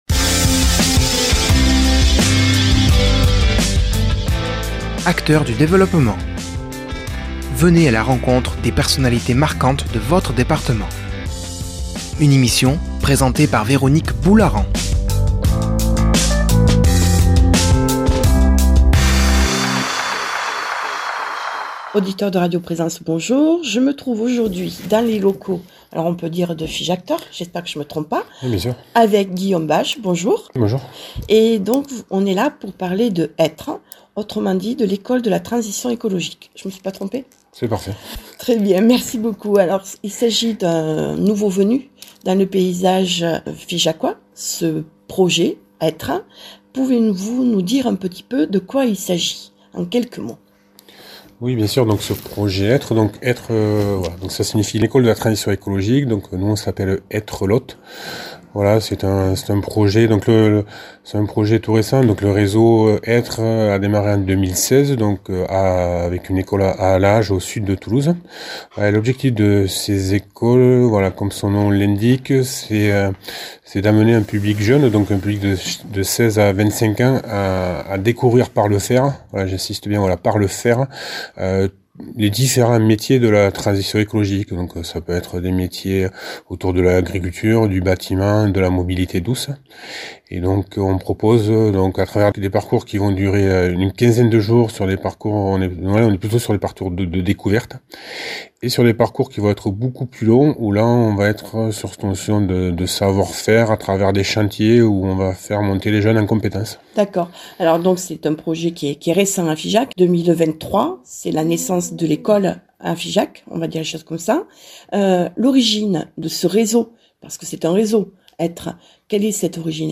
[ Rediffusion ] Aujourd’hui dans Acteur du Développement